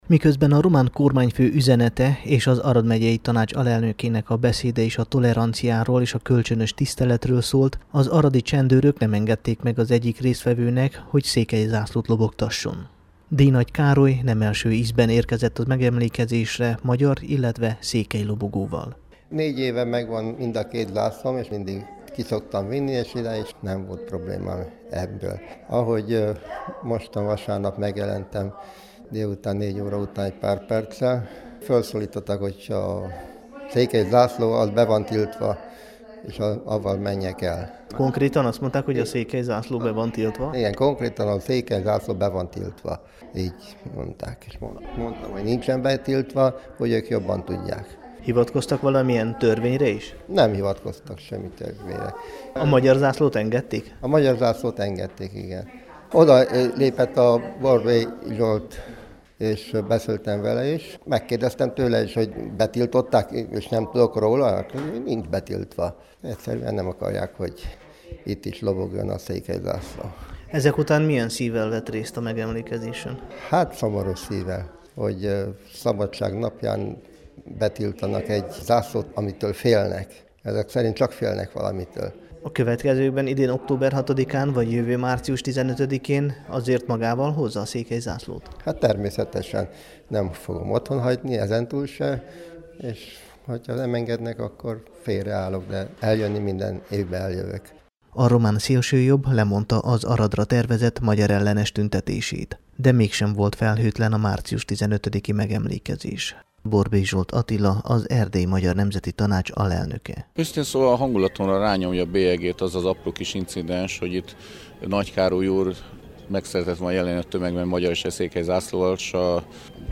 riportja